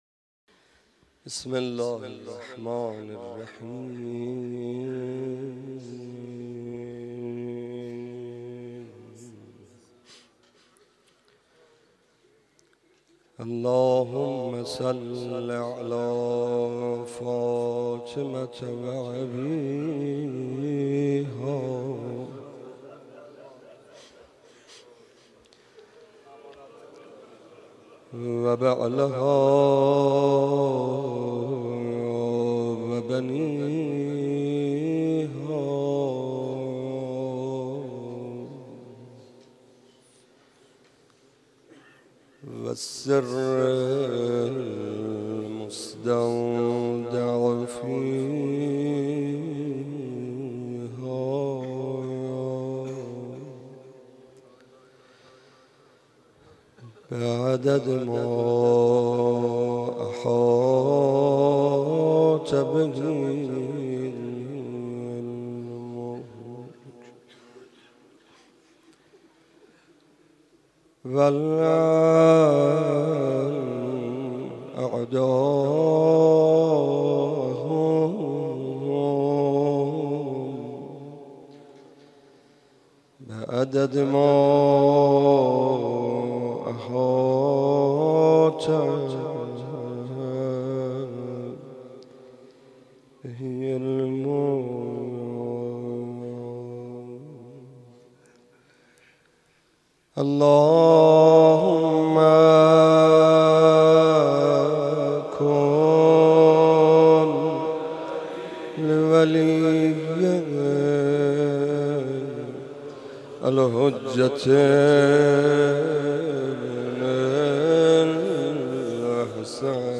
روضه حضرت علی اکبر (ع)